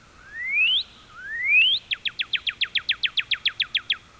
Birdsongs Sound Sweeter Because Throats Filter Out Messy Overtones
By operating their vocal mechanism as a dynamic acoustical resonator, they dramatically increase acoustic output and eliminate potentially distracting harmonic overtones.
cardinal.wav